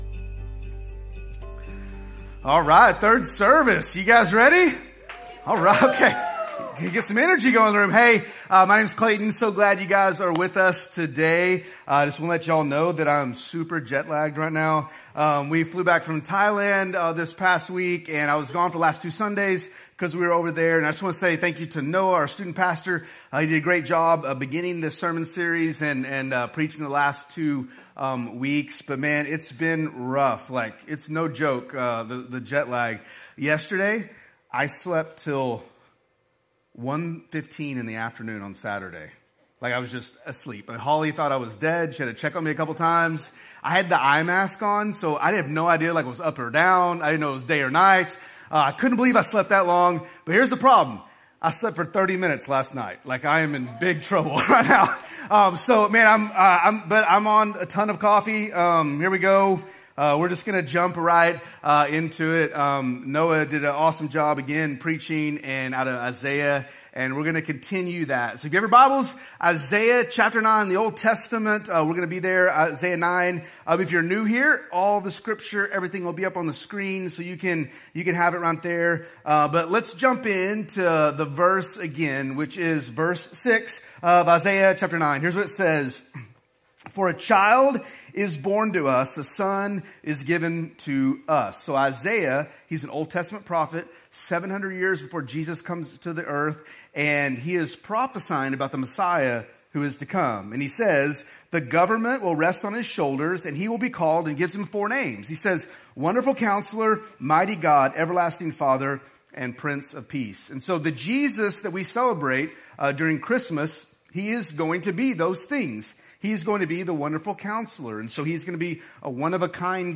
A message from the series "He Shall Be Called."